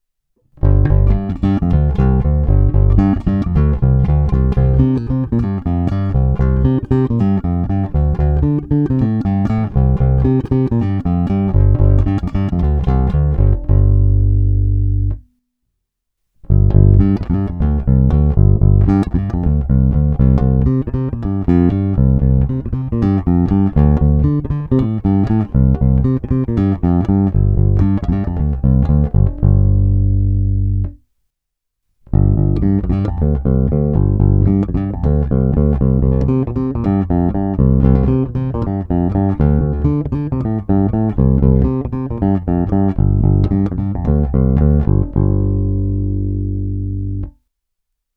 Má hodně výrazné středy, je poměrně agresívní, kousavý.
Není-li uvedeno jinak, následující ukázky byly provedeny rovnou do zvukové karty a s plně otevřenou tónovou clonou, jen normalizovány, jinak ponechány bez úprav.